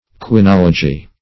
Meaning of quinology. quinology synonyms, pronunciation, spelling and more from Free Dictionary.
Search Result for " quinology" : The Collaborative International Dictionary of English v.0.48: Quinology \Qui*nol"o*gy\, n. [Quinine + -logy.] The science which treats of the cultivation of the cinchona, and of its use in medicine.